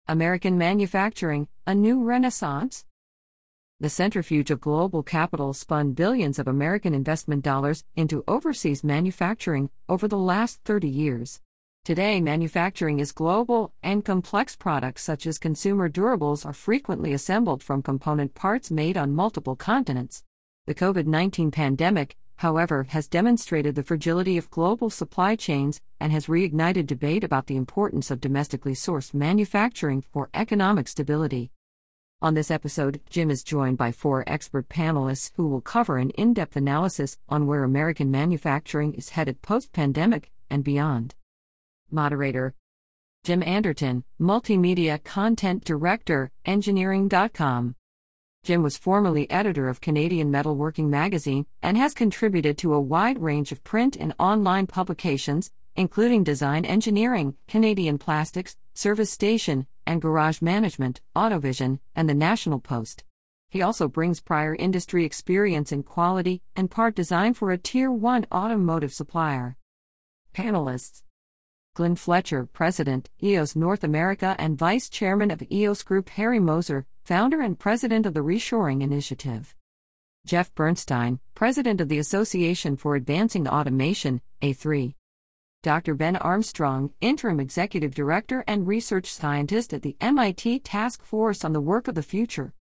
Four experts join this episode of the Engineering Roundtable with insights into the future of US manufacturing.